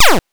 8 bits Elements / laser shot
laser_shot_12.wav